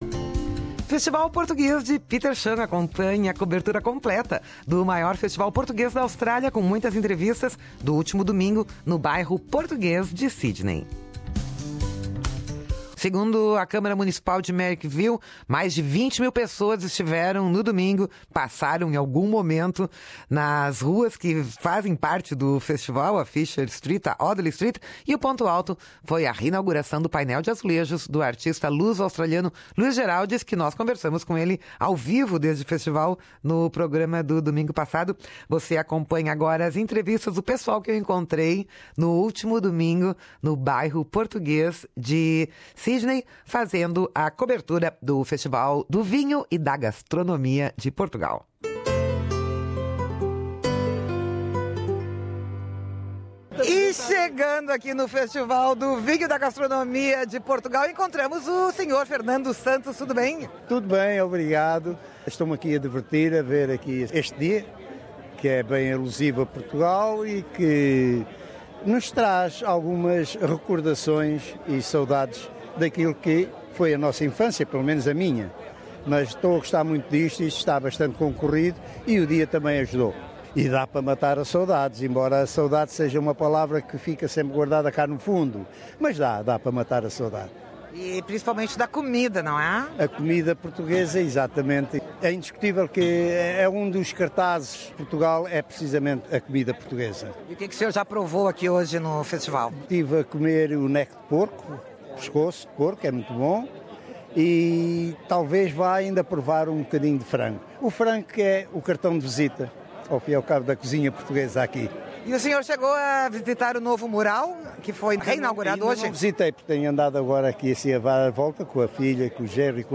Matar as saudades de Portugal, rever amigos de longa data e saborear os quitutes são os motivos que mais levam portugueses ao Festival anual de Petersham. Conversamos com portugueses no Festival do Vinho e da Gastronomia de Portugal, em Petersham, no domingo, 13 de março.